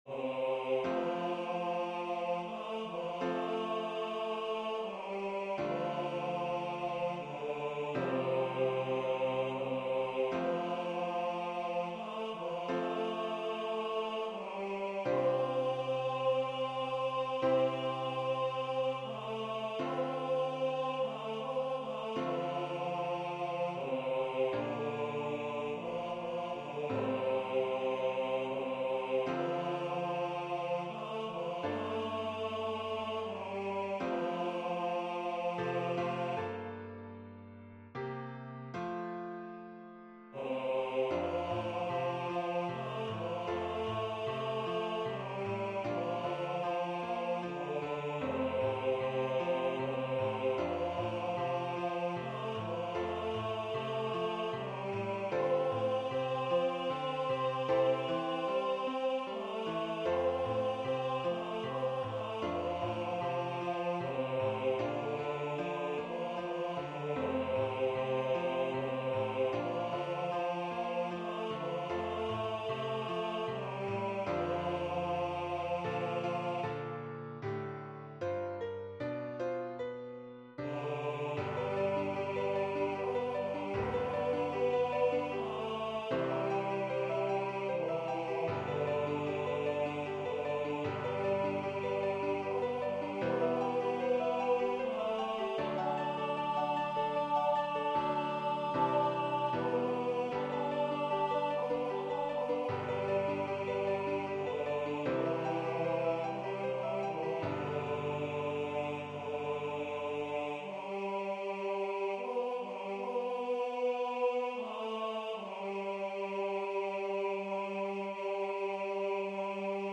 Vocal Solo
High Voice/Soprano